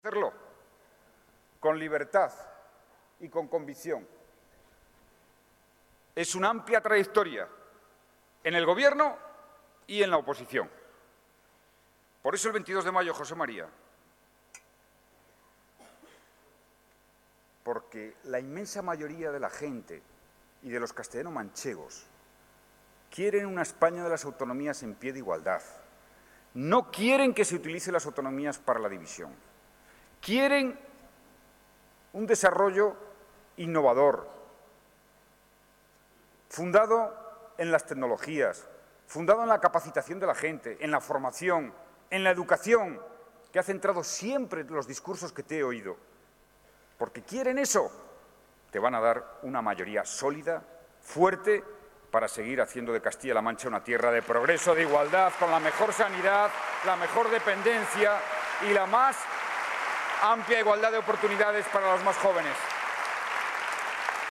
En el acto han participado más de 2.000 personas, que llenaron dos salas del Palacio de Congresos y Exposiciones de Albacete, entre los que había militantes y simpatizantes del partido, pero también representantes de la sociedad, de los empresarios, los sindicatos, de los vecinos, de las asociaciones socio sanitarias, que recibieron un aplauso del público, por ser compañeros de viaje en el trabajo por Albacete y Castilla-La Mancha.